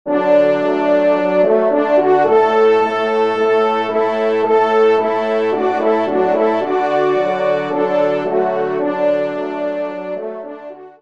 Genre : Divertissement pour Trompes ou Cors
ENSEMBLE